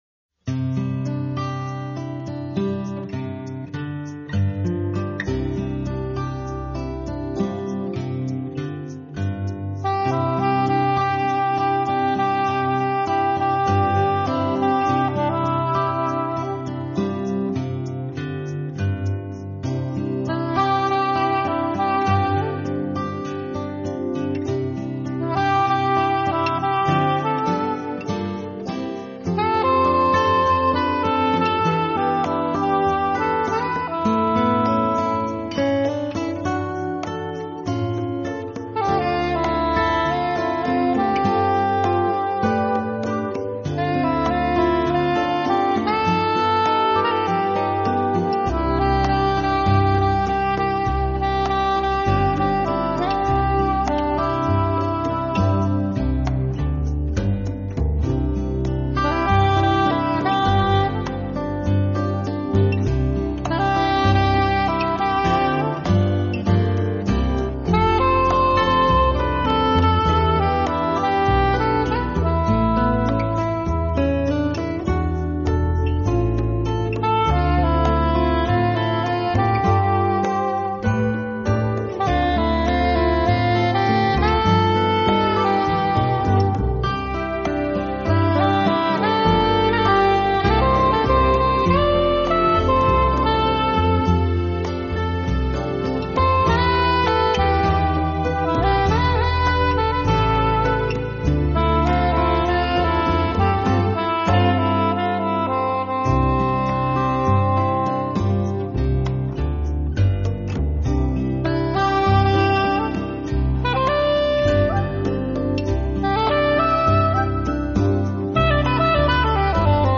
他们把这些原本流行的歌曲改编为带有明显爵士味道的纯音乐，
拥有了爵士音乐特有的浪漫，却没有爵士音乐的吃力和震荡，
这些作品却显得那么的舒缓和柔美，轻盈飘荡间，